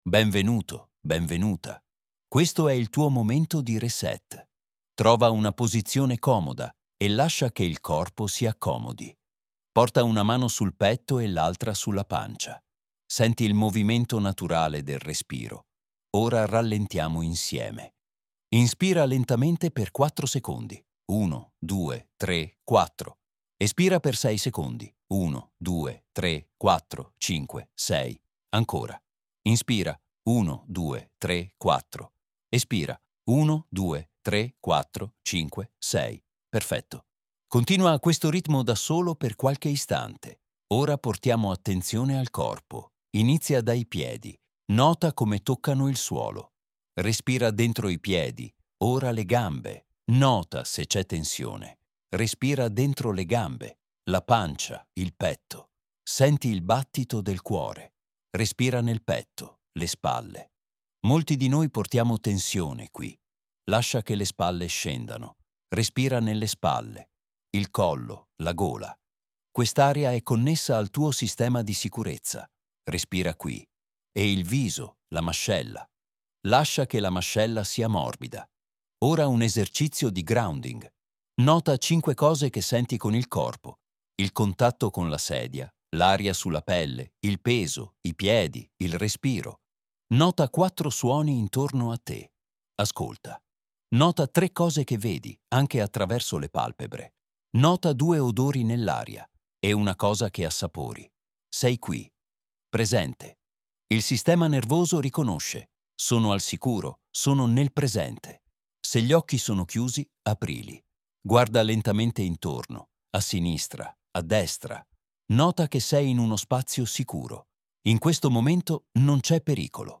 Esercizio guidato di respirazione, grounding e regolazione vagale